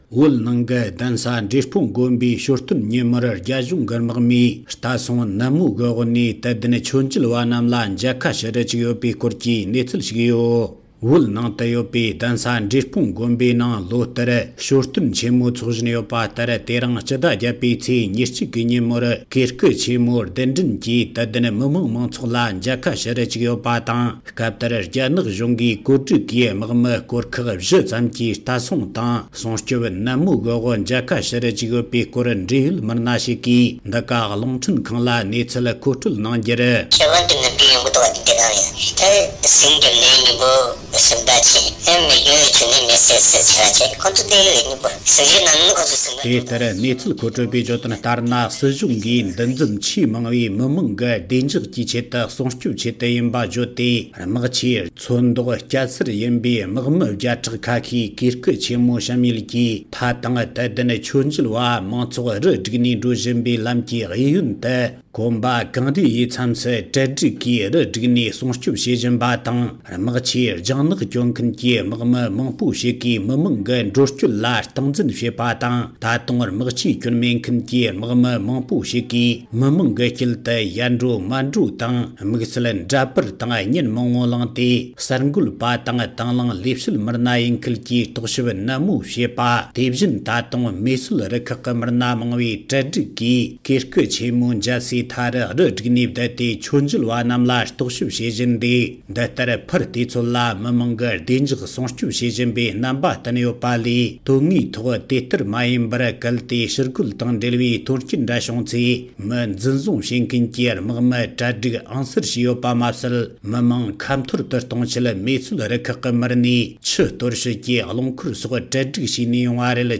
སྒྲ་ལྡན་གསར་འགྱུར། སྒྲ་ཕབ་ལེན།
གསར་འགོད་པ